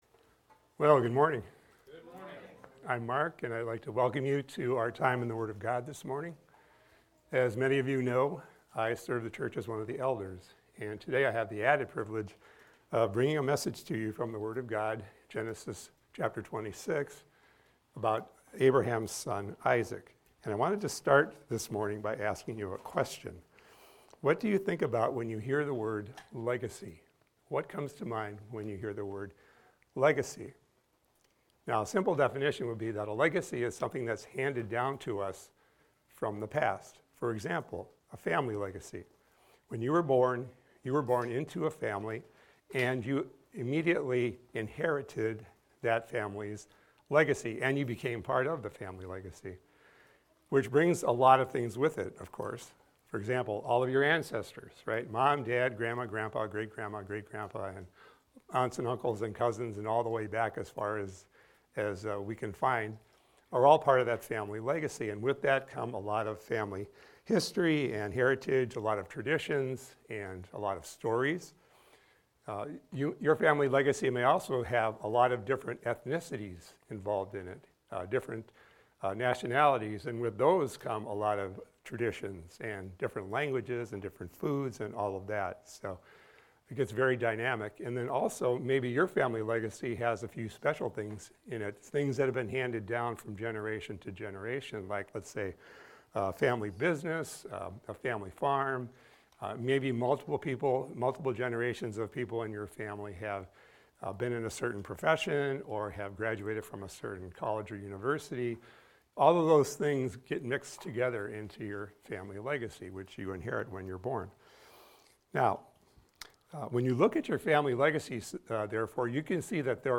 This is a recording of a sermon titled, "Living With A Legacy."